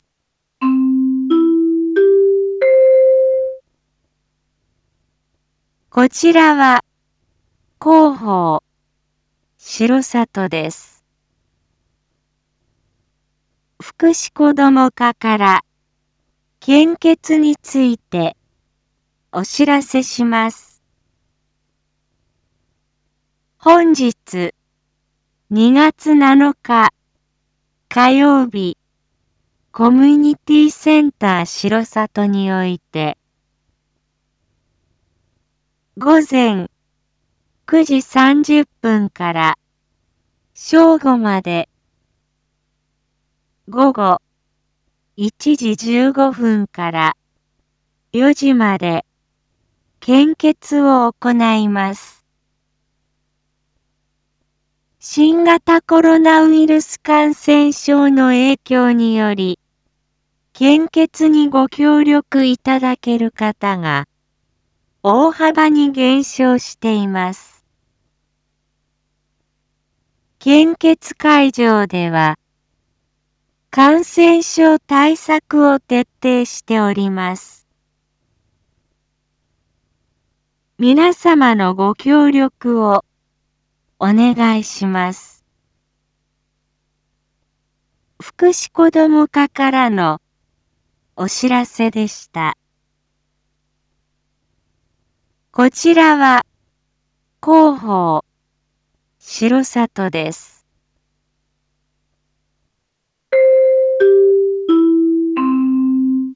Back Home 一般放送情報 音声放送 再生 一般放送情報 登録日時：2023-02-07 07:01:47 タイトル：R5.2.7 7時放送分 インフォメーション：こちらは広報しろさとです。 福祉こども課から献血についてお知らせします。